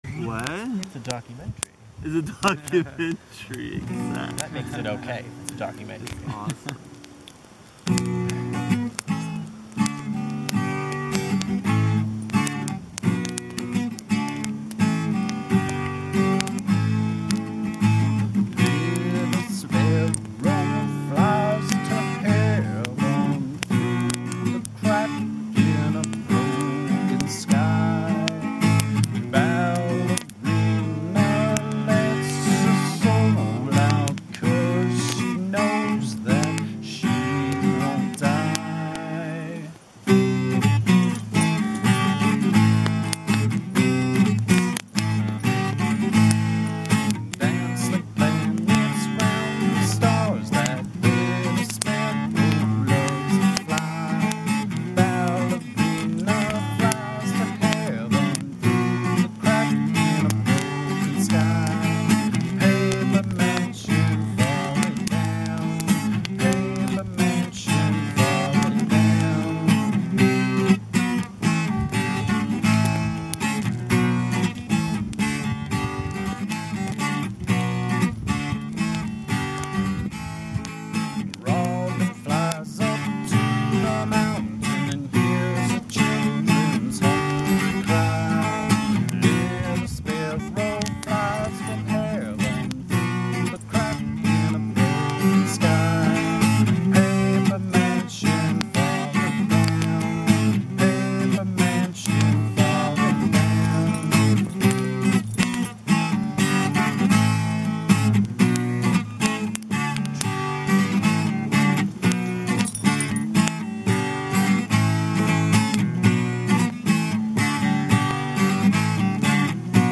Despite my last-minute efforts, I couldn’t rig up a proper microphone for my minidisk player/recorder. Therefore, we were relying solely upon the audio from my video camera which was set up across the fire. The fire crackles throughout, the dog’s tags jingle occasionally, and when the quite substantial wind gusts, it disrupts the audio levels… but to those who were there, it was perfectly sweet!